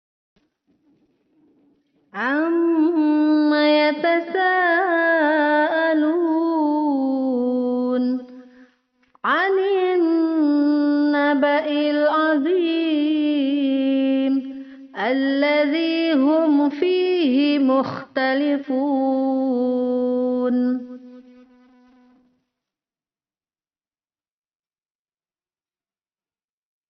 Mad ini terjadi ketika berhenti (waqof) di akhir ayat sehingga mematikan huruf terakhir yang ada mad asli. Panjangnya boleh 2, 4 dan 6 tapi harus konsisten di semua ayat dalam satu surat.